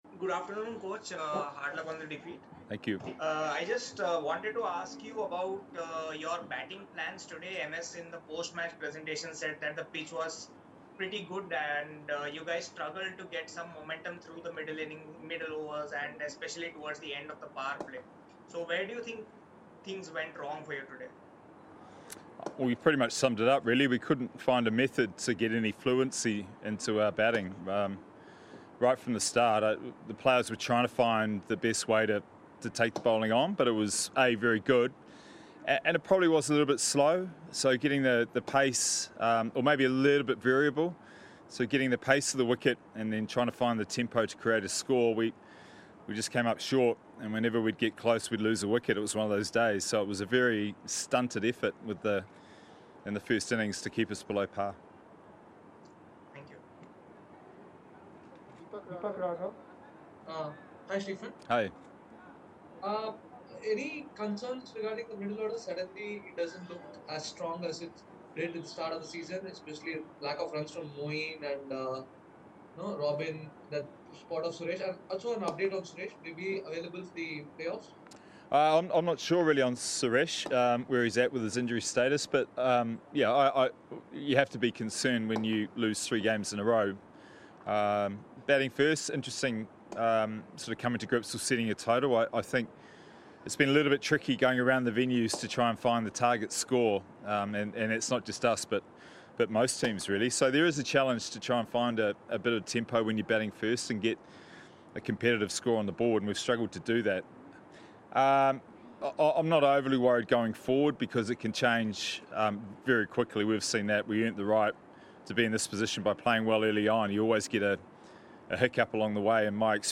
Stephen Fleming, Head Coach of CSK addressed the media at the end of the game